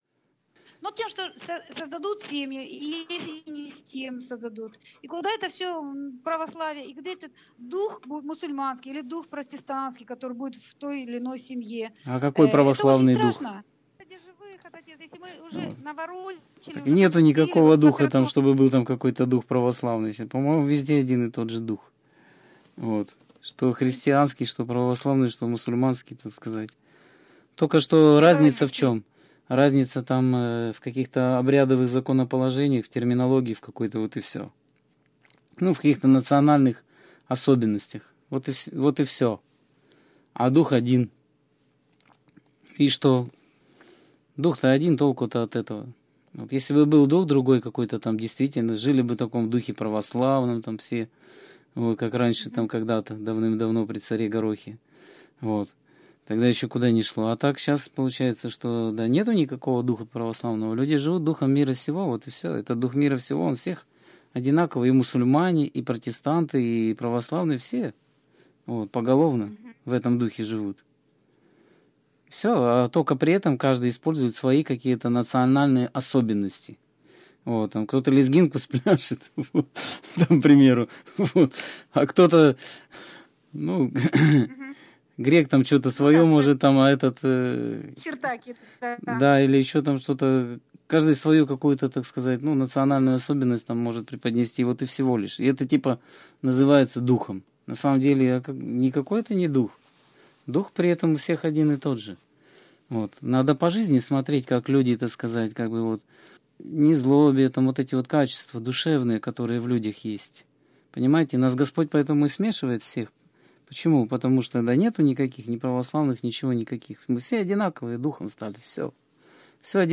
Скайп-беседа 6.04.2013